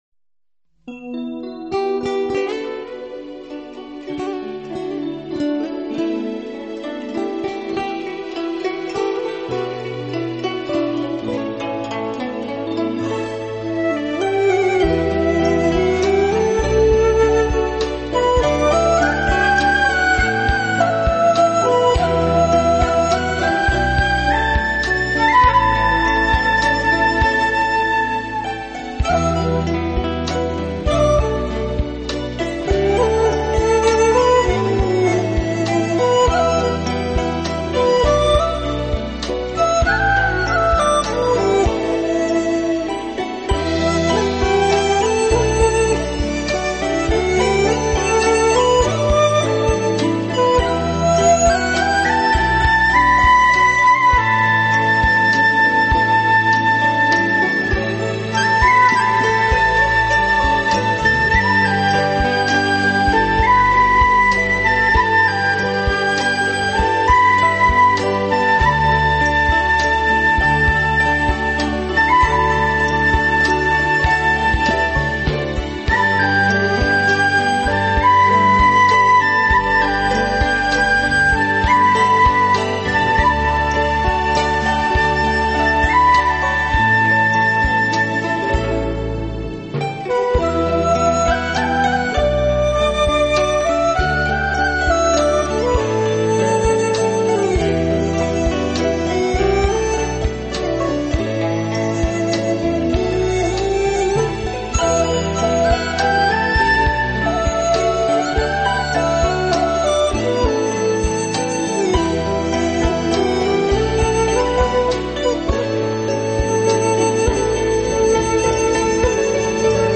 现代佳丽组合的民族音乐跨界远征
古典乐器演绎新民乐曲风绚丽多采